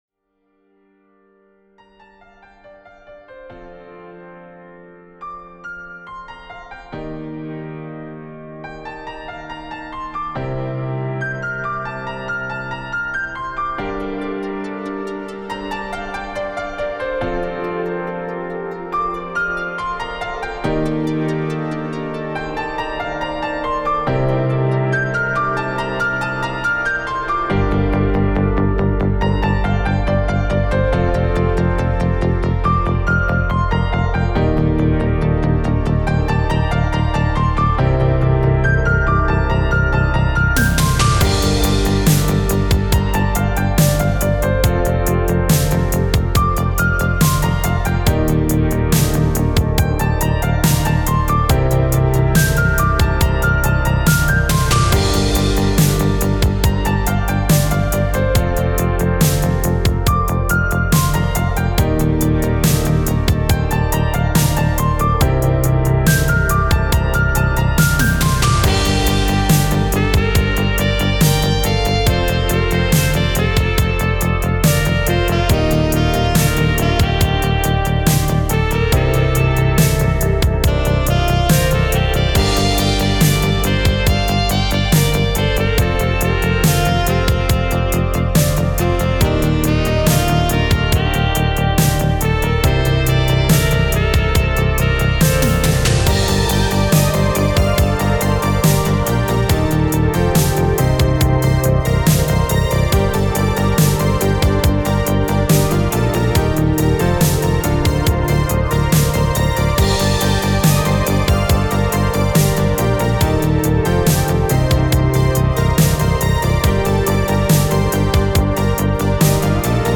Genre: Electronic, Synthwave.